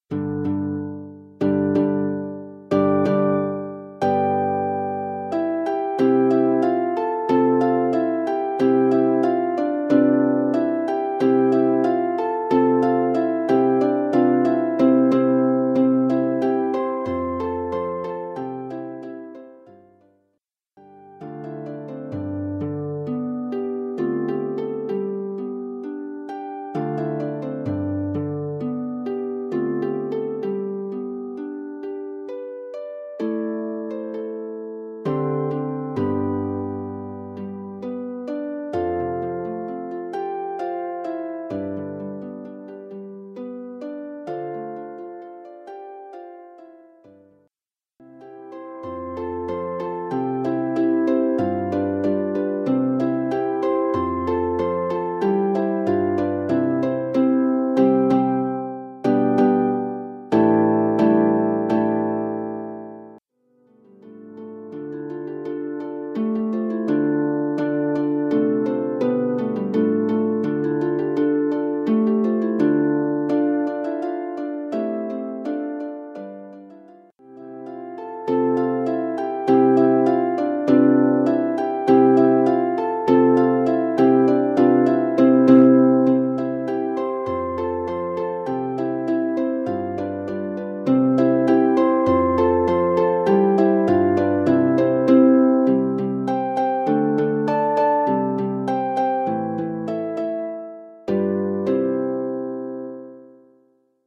Arranged for four lever or pedal harps
three traditional Welsh melodies